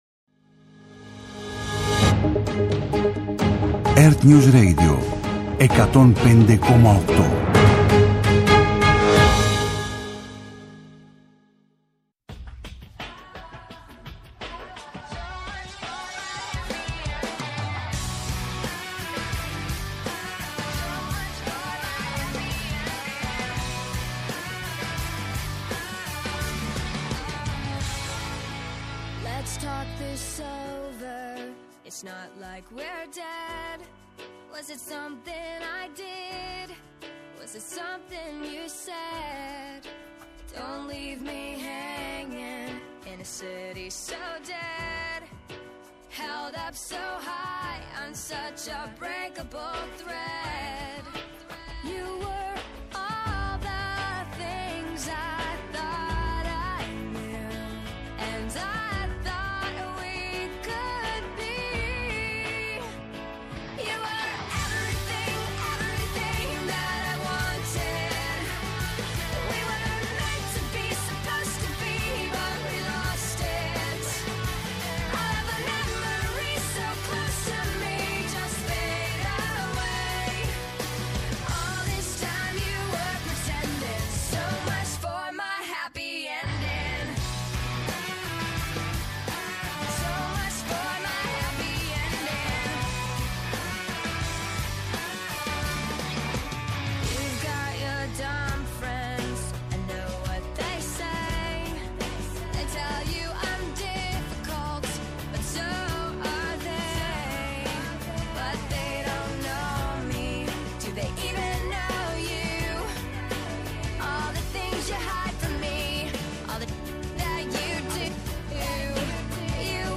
-Απόσπασμα από την ενημέρωση των πολιτικών συντακτών από τον Κυβερνητικό Εκπρόσωπο